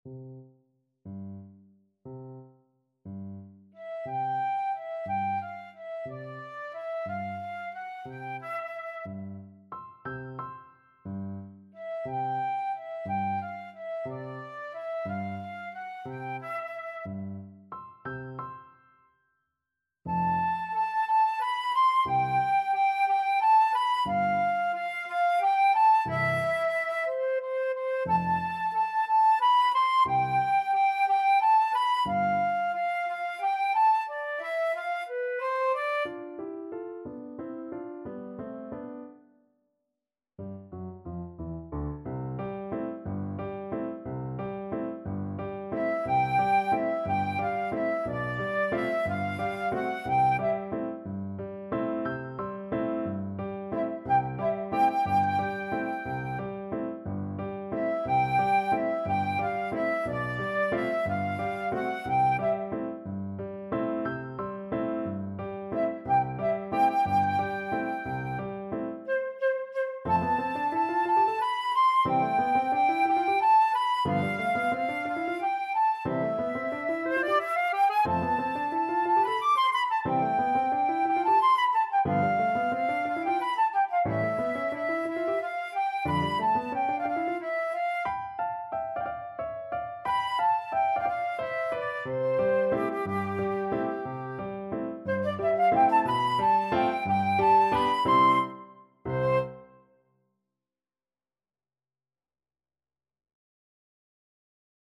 Flute
C major (Sounding Pitch) (View more C major Music for Flute )
Pochissimo pi mosso = 144 . =60
6/8 (View more 6/8 Music)
Classical (View more Classical Flute Music)